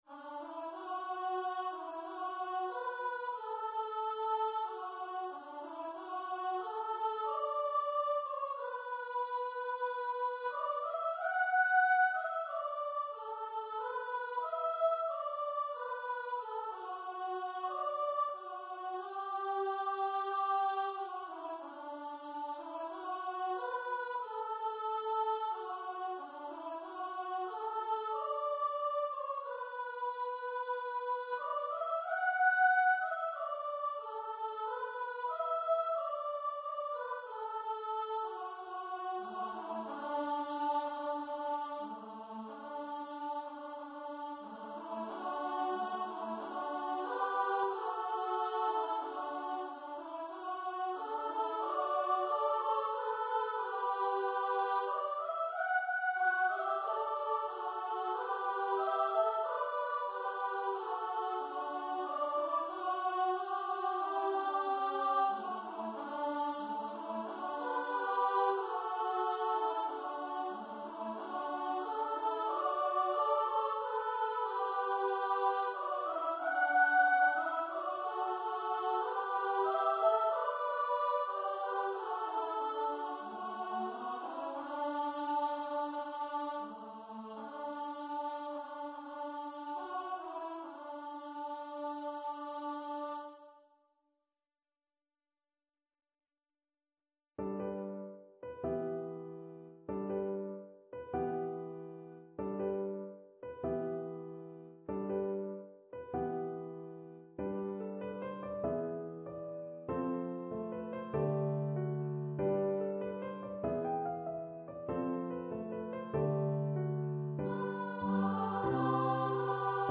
for upper voice choir
for two part (SA) unaccompanied voices
for two part (SA) choir with piano accompaniment
Choir - 2 part upper voices